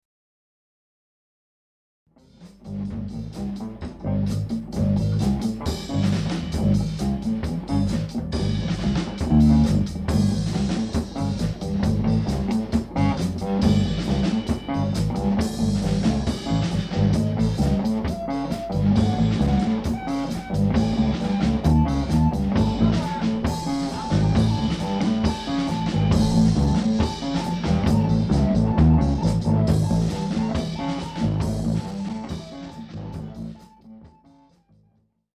e-bass
guitar
voc, perc.
perc., sax, flute
tuba, microsynth
drums, perc.